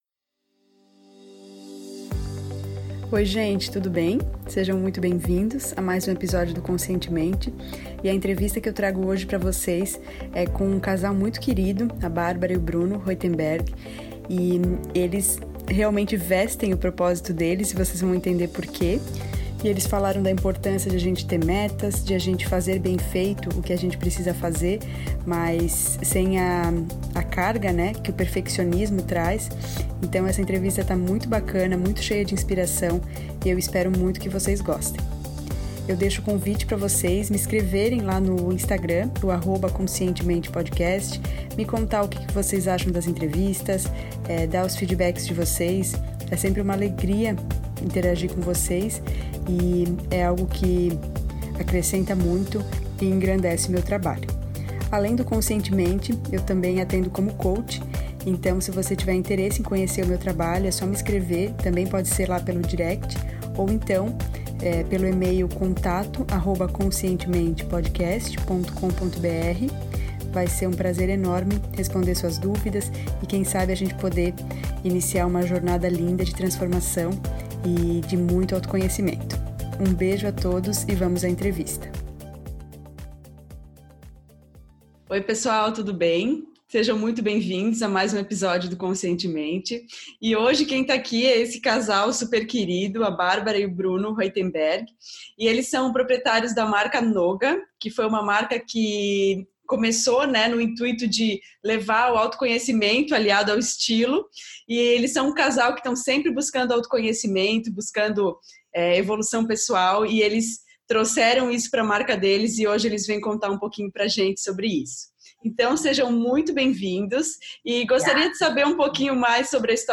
Na entrevista eles nos contam um pouco sobre: O principal passo para começar a jornada do autoconhecimento; O que impede as pessoas de avançarem em relação ao seu desenvolvimento pessoal; O que mais contribui para as pessoas alcançarem mais realização pessoal; O melhor conselho que eles receberam na vida.